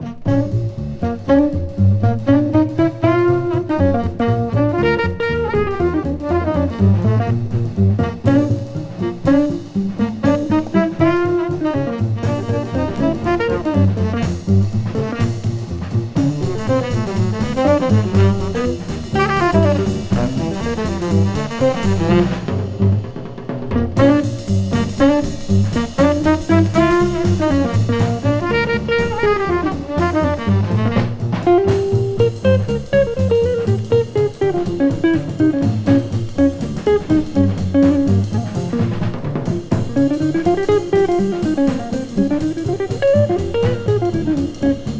ギター、ベース、ドラム、フルートやテナーサックスによる演奏。
Jazz　USA　12inchレコード　33rpm　Mono